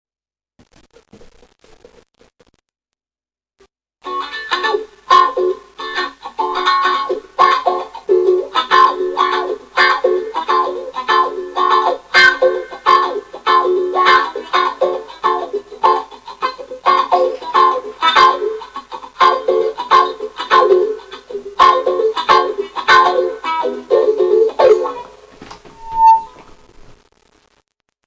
To this end I have amp-ed up my Ukulele with a bug and plugged it thru my cry-baby wah-wah pedal into a small amplifier.
uke-wahwah.mp3